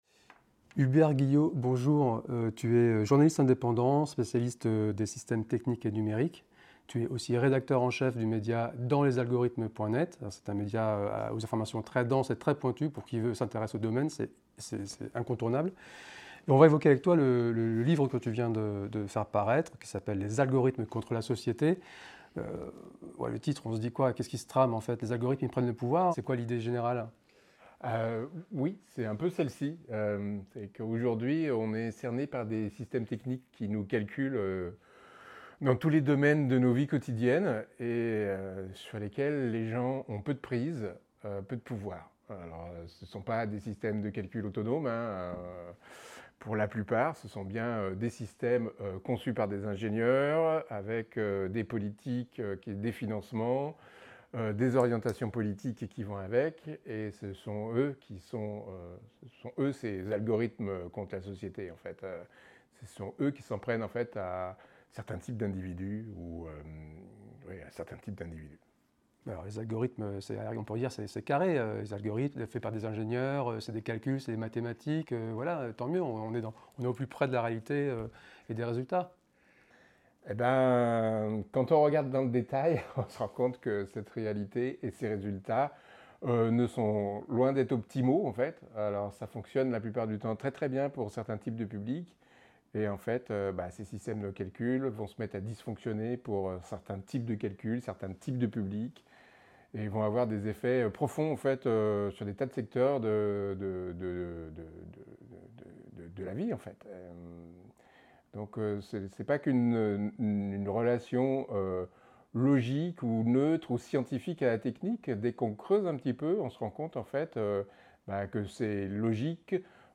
interviouve